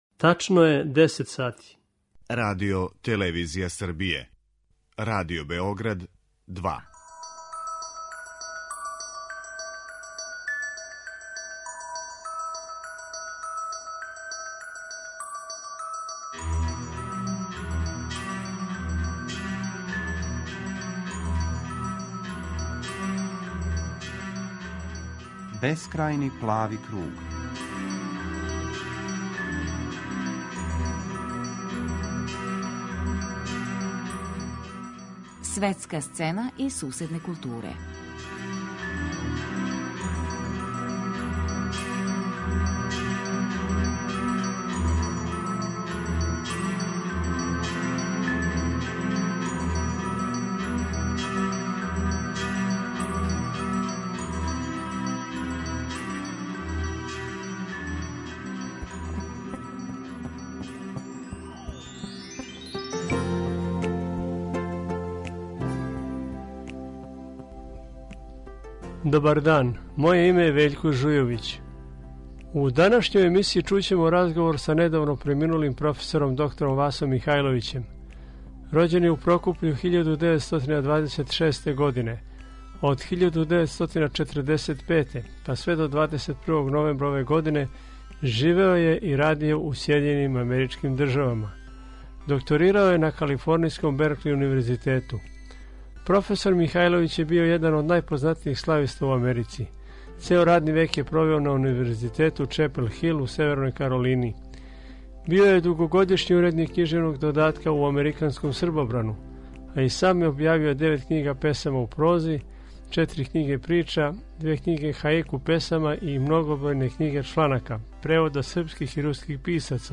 Емитоваћемо разговор